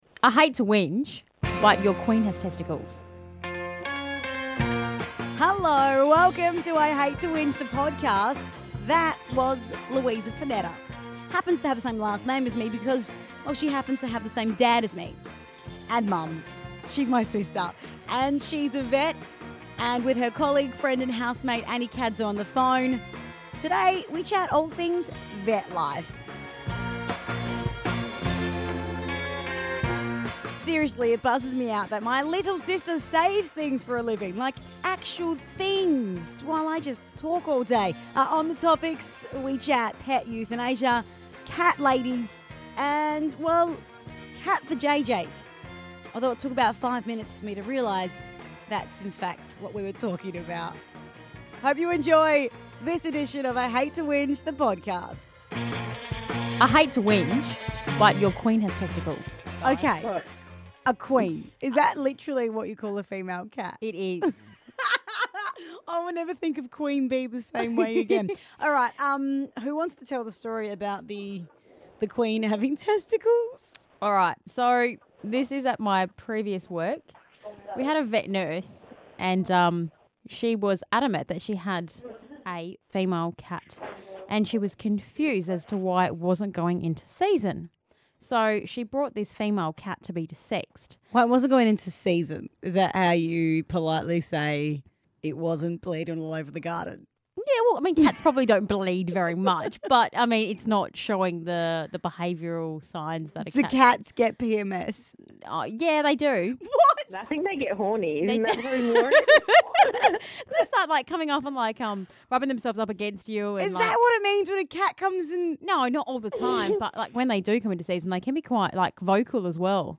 Vets